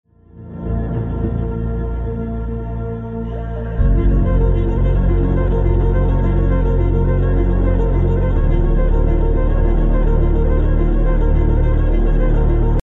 Cutting every element within the sound effects free download